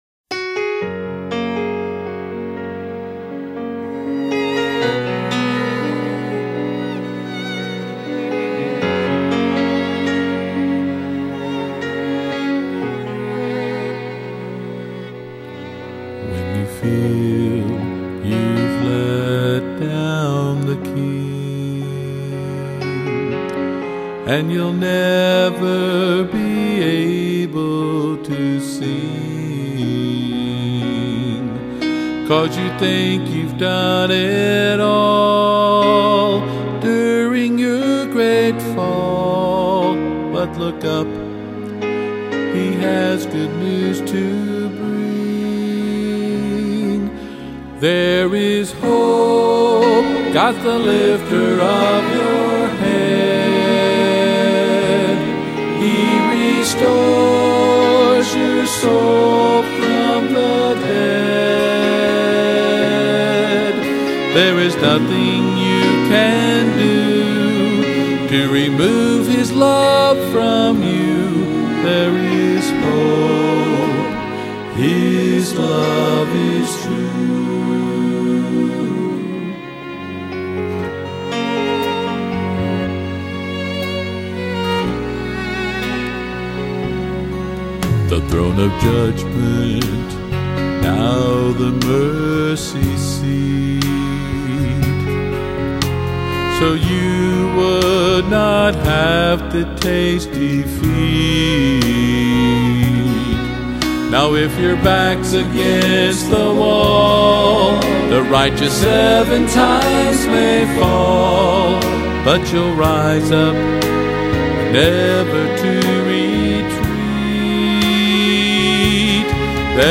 Southern Gospel Songwriter